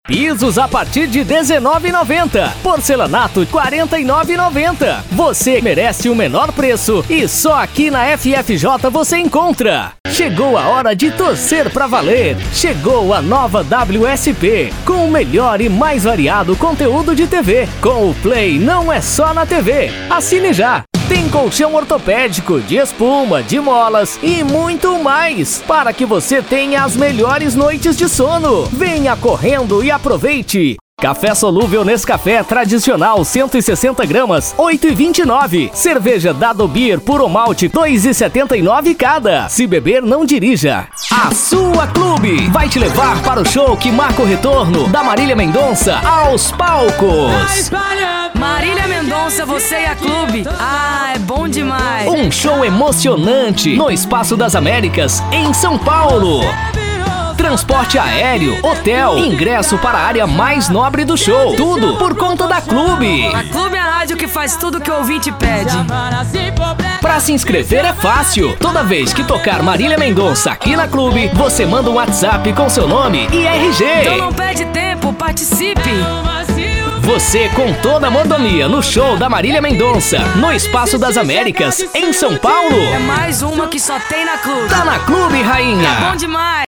VOZES MASCULINAS
Estilos: Padrão Varejão